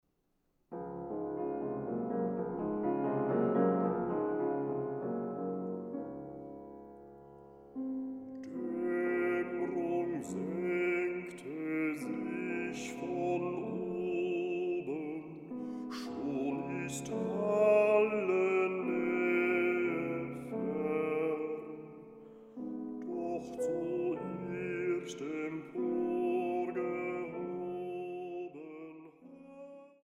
Bassbariton
Klavier